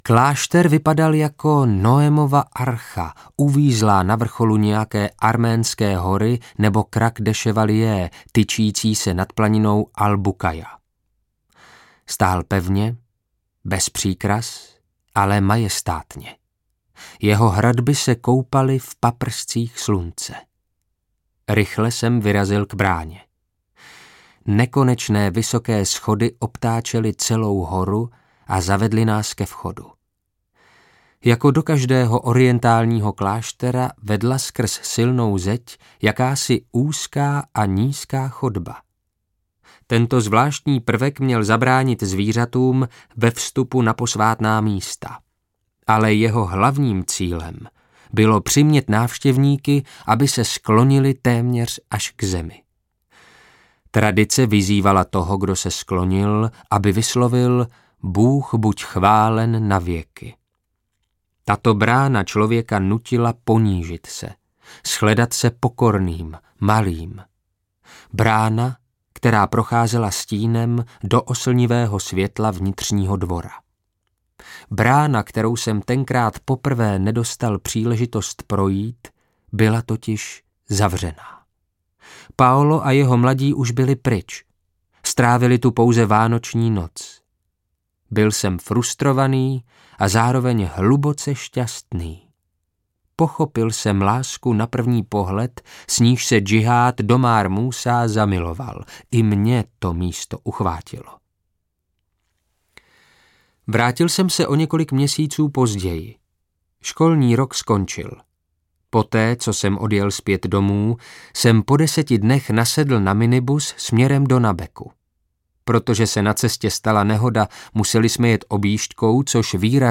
Mnich v zajetí islamistů audiokniha
Ukázka z knihy